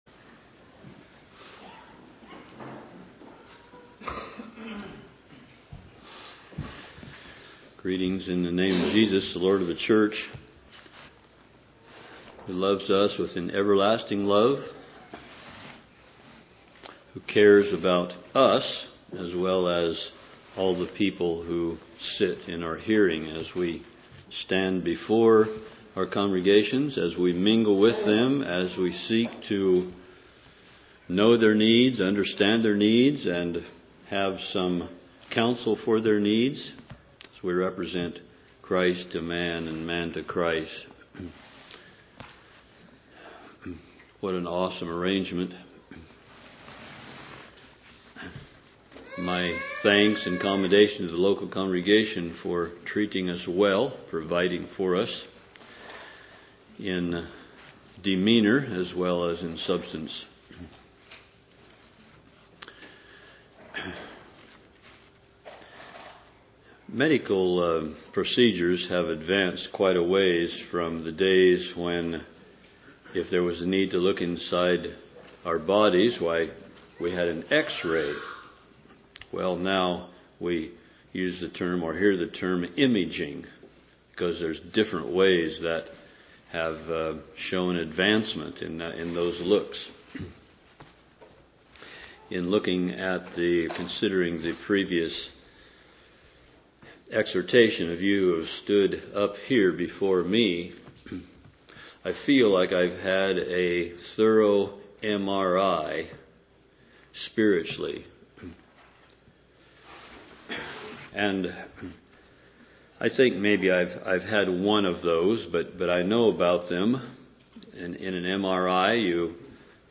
2014 Ministers Meetings